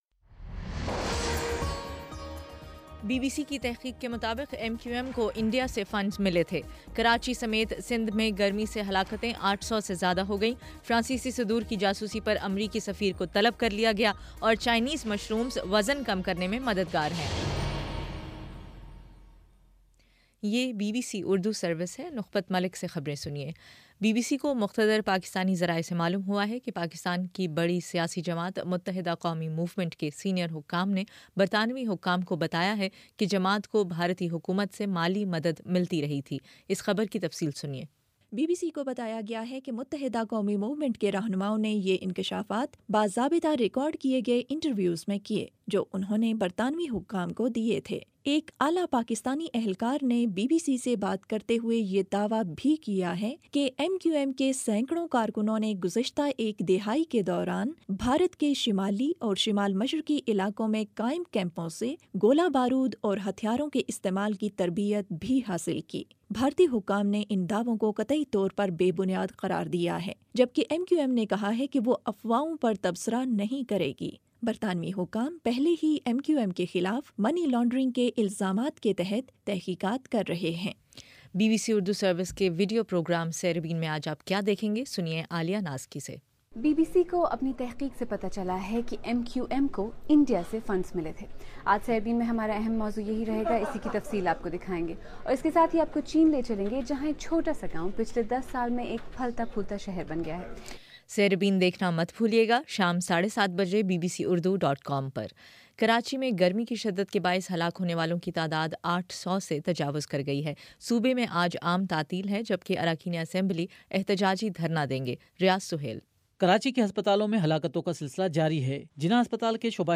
جون 24: شام چھ بجے کا نیوز بُلیٹن